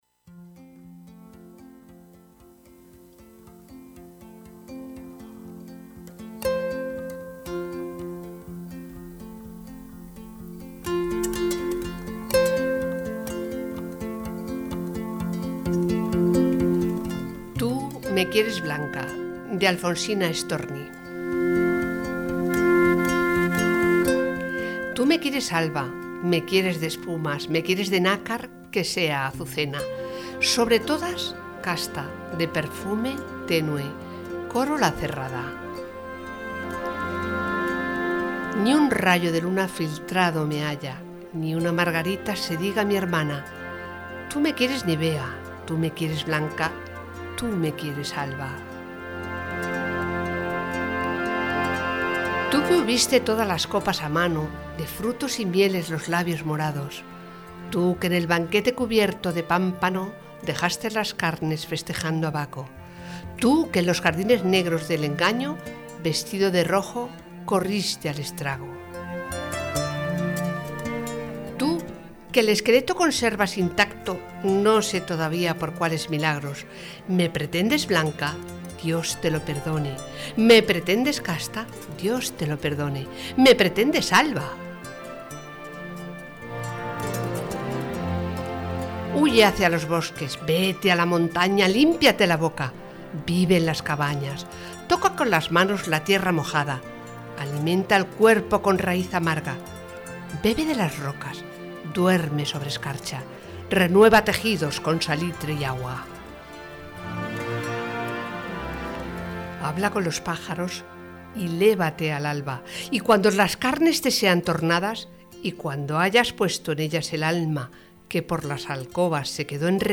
Viento a Favor presenta un nuevo episodio de poesía